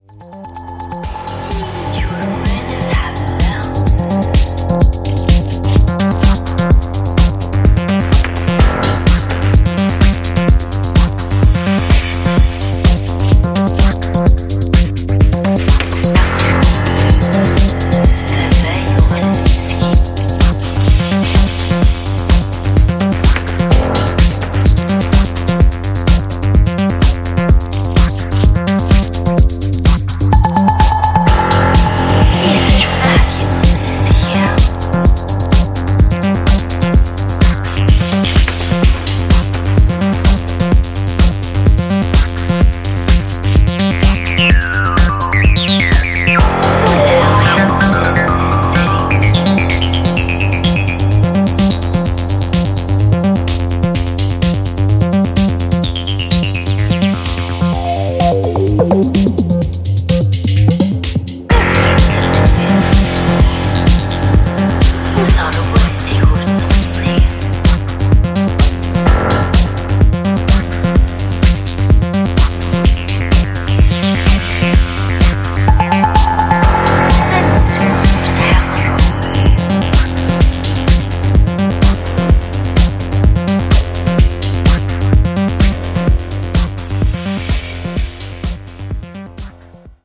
本作では、EBMやアシッド等の要素を盛り込んだダークでソリッドなエレクトロ・ハウスを展開しています。